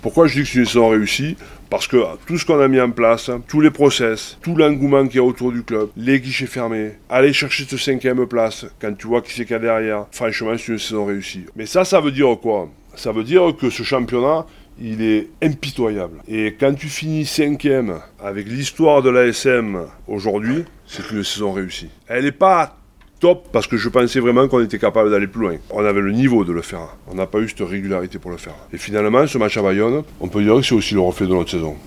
Ce sont par ces mots que Christophe Urios a commencé sa conférence de presse pour faire le bilan de la saison 2024-25.